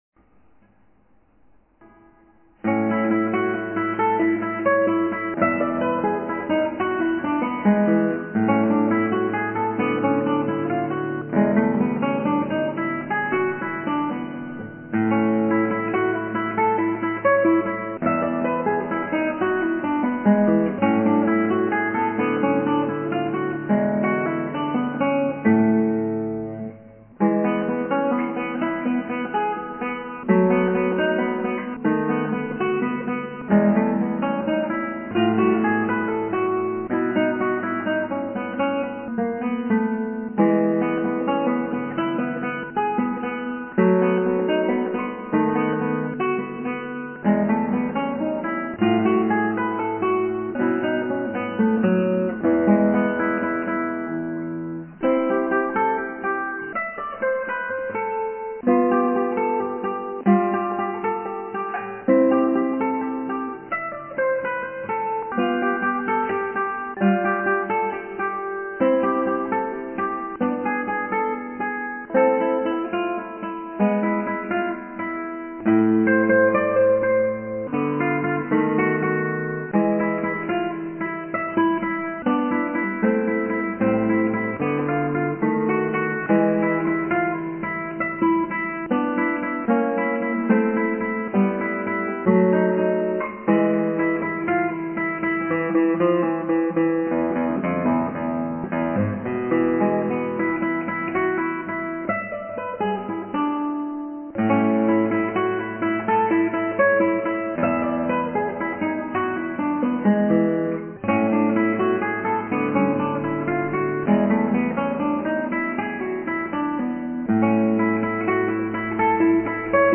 カルカッシのエチュード２３番、アルカンヘルで